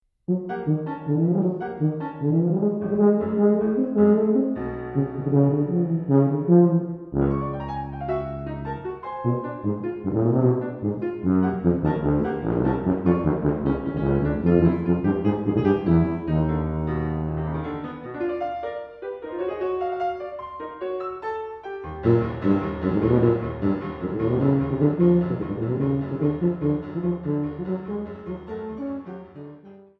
Piano
Sonata for Tuba and Piano